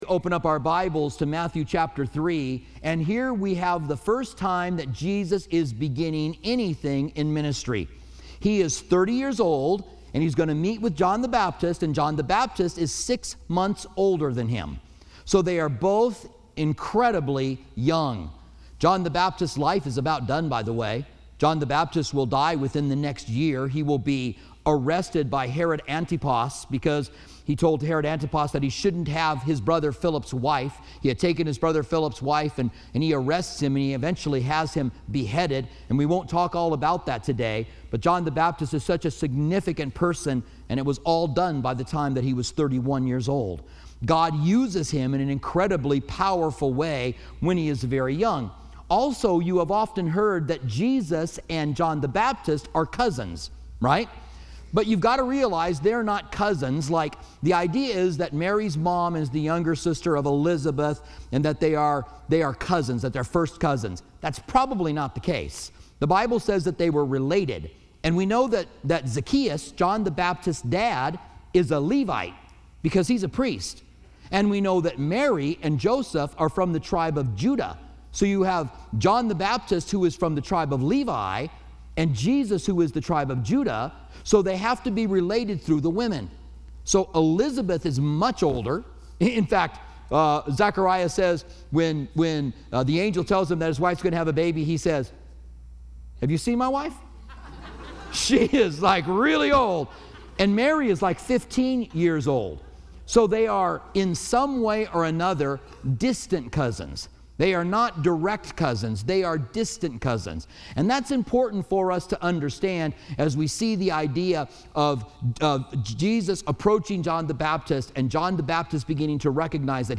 preaching the first Sunday returning from the Covid-19 shutdown.